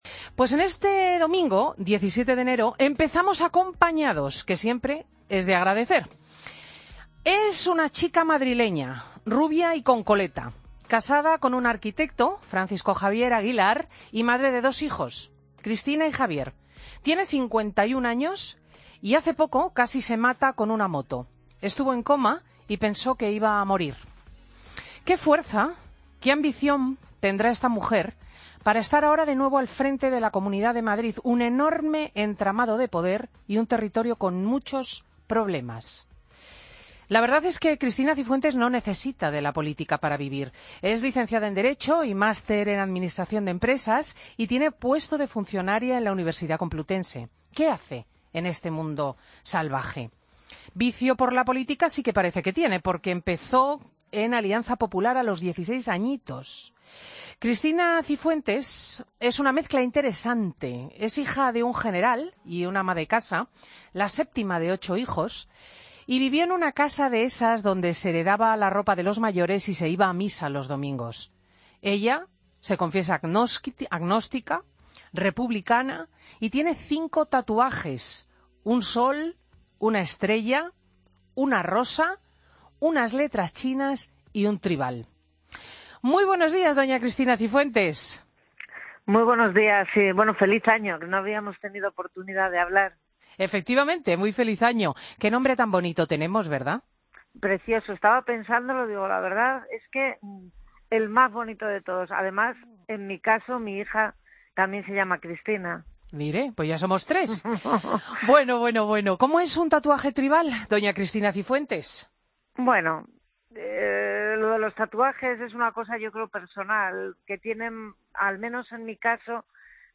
Escucha la entrevista a Cristina Cifuentes, Presidenta de la Comunidad de Madrid, en Fin de Semana Cope.